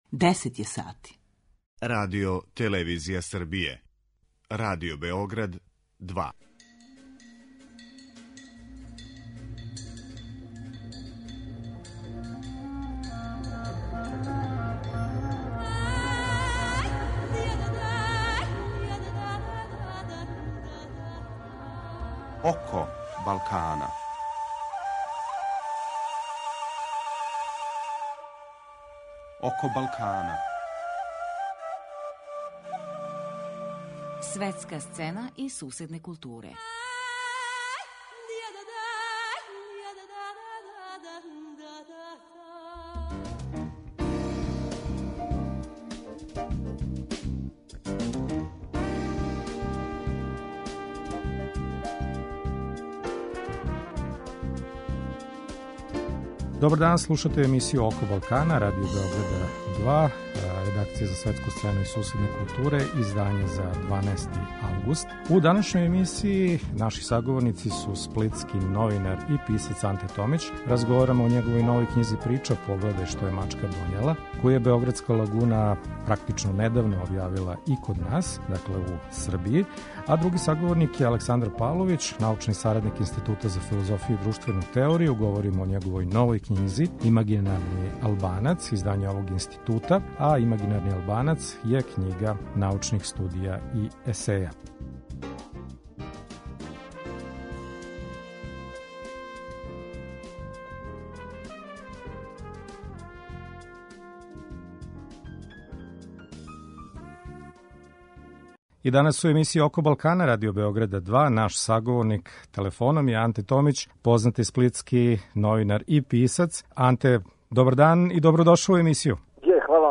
У данашњој емисији наш први саговорник је познати сплитски прозни писац и колумниста Анте Томић.